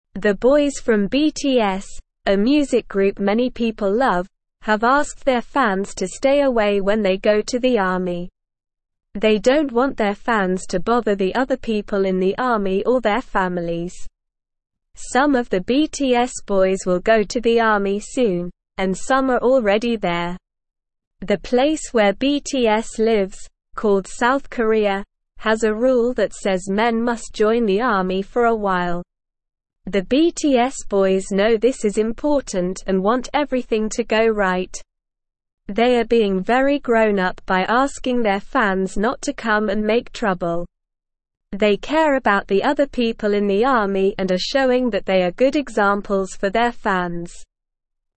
Slow
English-Newsroom-Beginner-SLOW-Reading-BTS-Boys-Ask-Fans-to-Stay-Away-from-Army.mp3